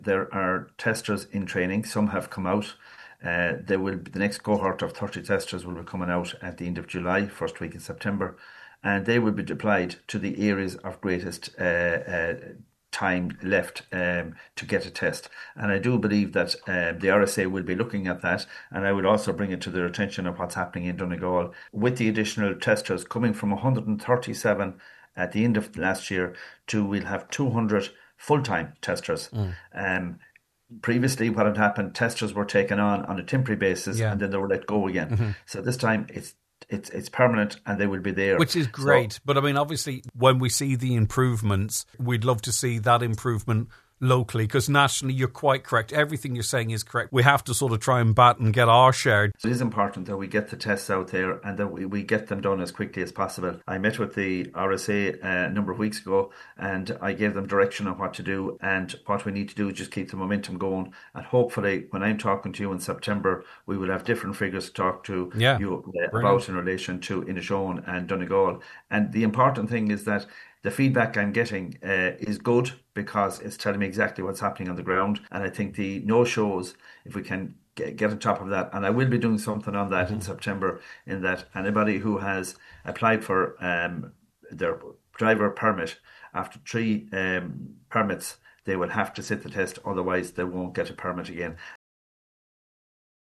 Junior Transport Minister Seán Canney says work is being done to reduce waiting lists and it’s hoped by September there will be change in Donegal: